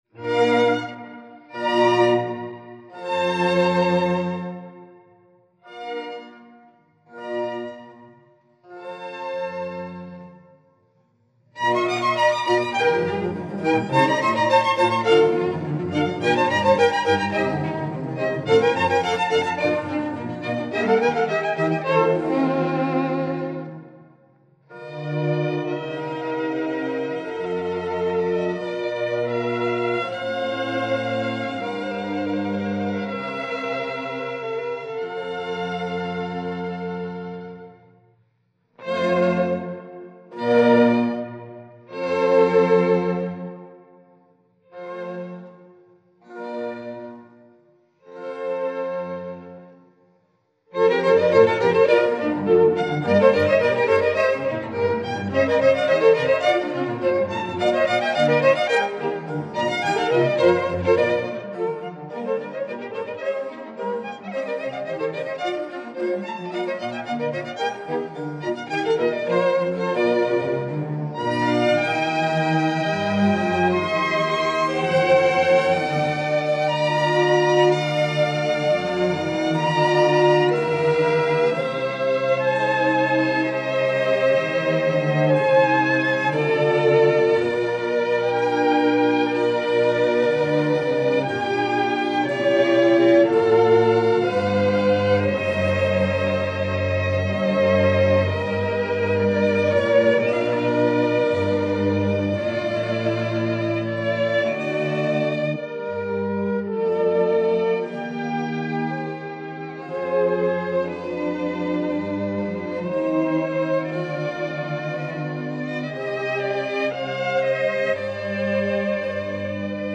Enrico ALBICASTRO (c.1670– 1738): Concerto à 4 in B– flat Major, Op. 7, No. 6 2
Violins
Cello
Harpsichord